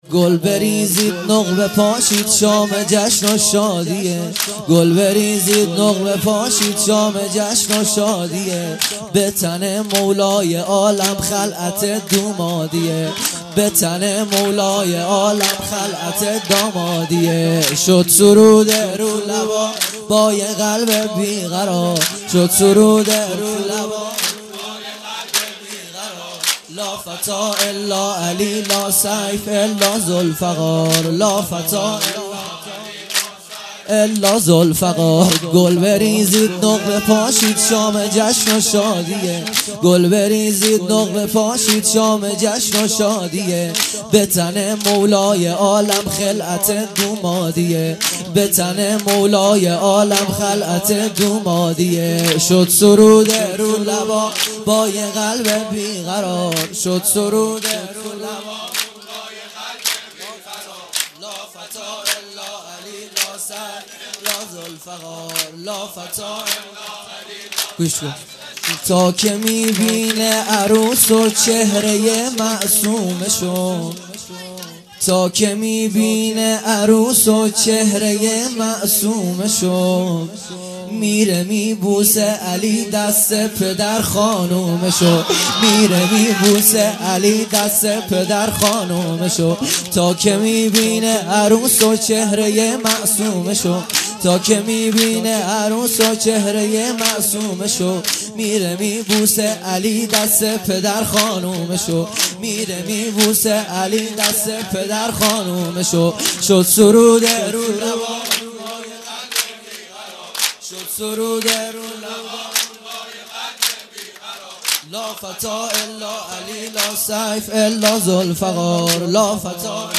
سرود ۲ |گل بریزید نقل بپاشید مداح